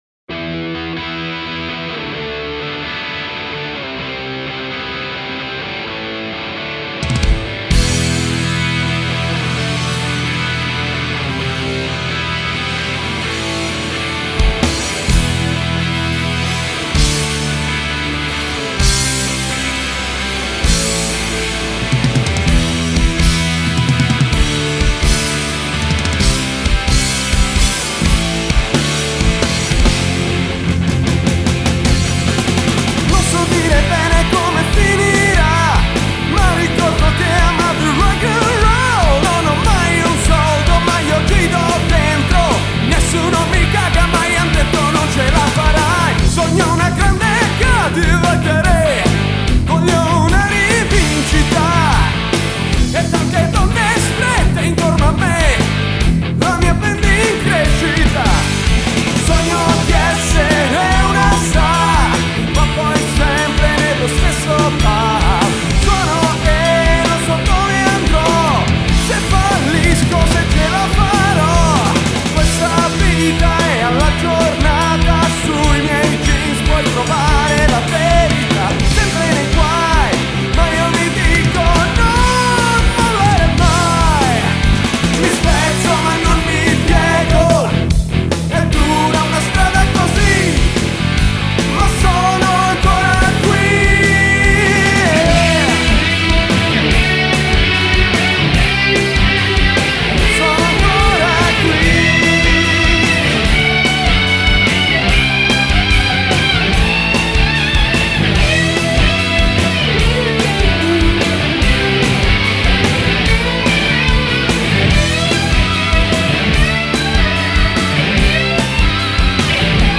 SONO ANCORA QUI - brano preparato per le selezioni al festival di Napoli nel 2003